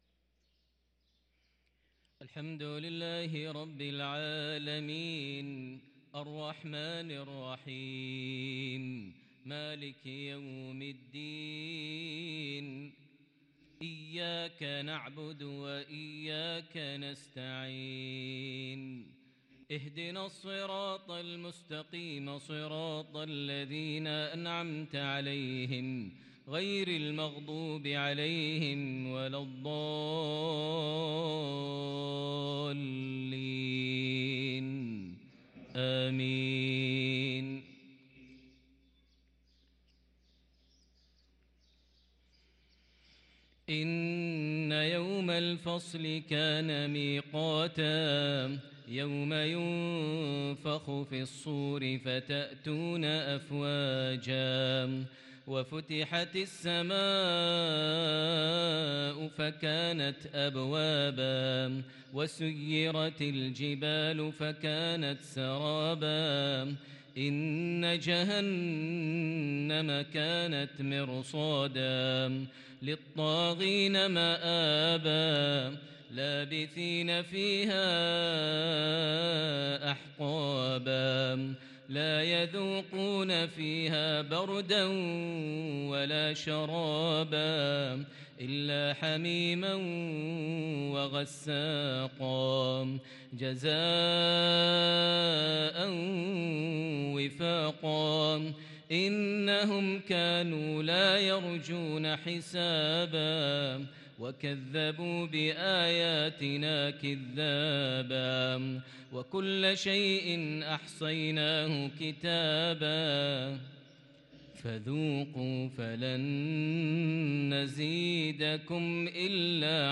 صلاة المغرب للقارئ ماهر المعيقلي 8 جمادي الآخر 1444 هـ
تِلَاوَات الْحَرَمَيْن .